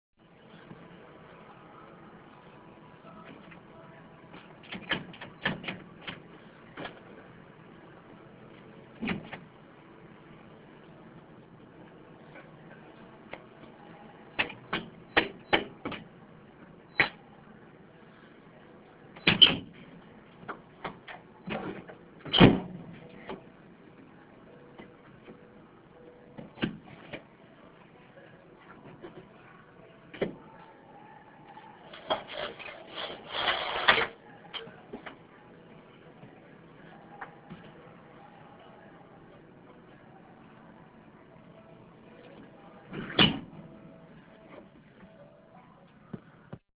Field Recording
Sounds Heard: Me jiggling the window trying to get it to close, me hitting a can of paint against the hinge multiple times, the fan blowing, the window shutting, me locking it, the blinds coming down and closing, music from a car outside.
Field-Recording-Window-Struggle-MP3.mp3